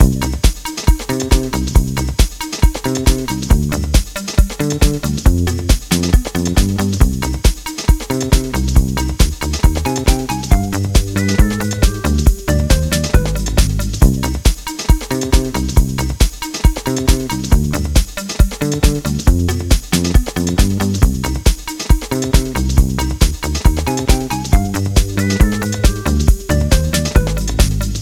Ok, let’s go, with the bass guitar at the foreground.
Loop 10: speed MAX